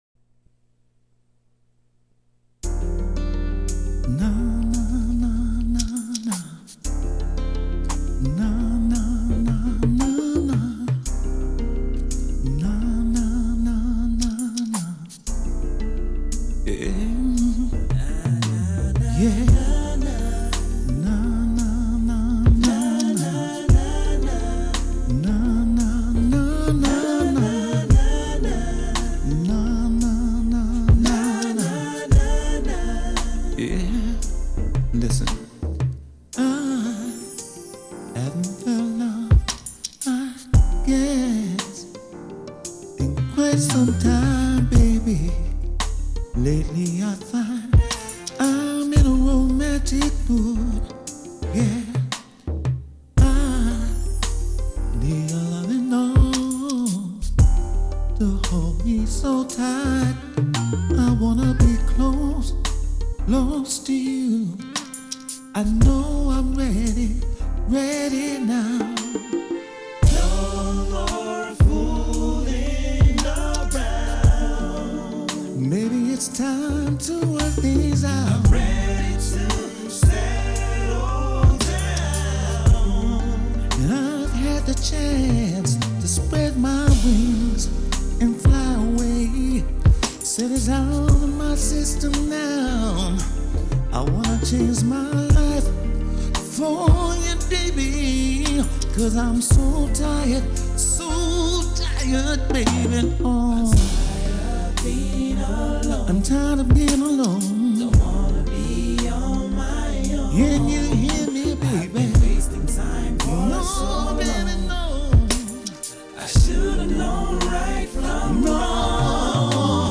Home > Music > Rnb > Bright > Smooth > Laid Back